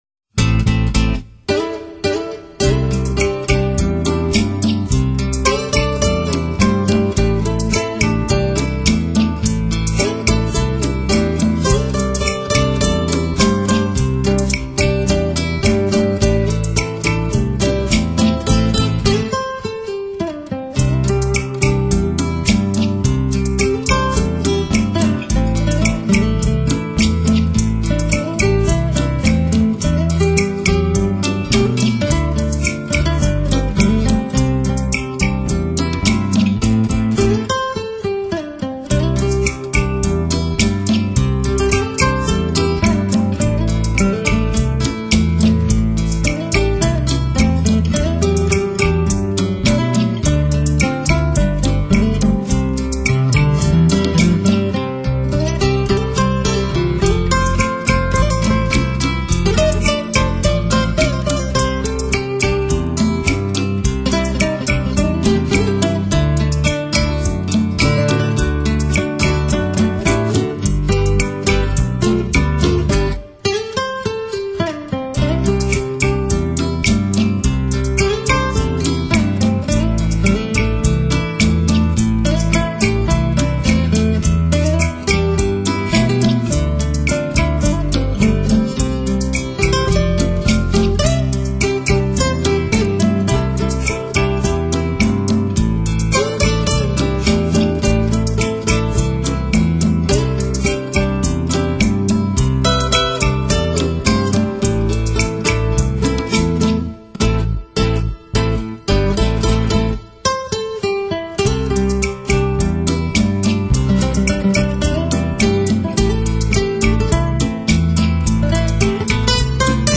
音乐类型： NEW AGE
这是一张越听越上瘾的音乐专辑，它采用了夏威夷吉他音乐，
轻漫的吉他配合节奏既鲜明又清雅的多种夏威夷式的敲击乐器，
显得格外舒缓怡情。
专辑的音乐演奏由一个小型的乐队担任：一把主旋吉他、一把伴奏吉他，
一把低音大提琴，还有形形的敲击乐。
全碟风格自然浪漫，舒畅迷人，有着至高无尚的音乐享受，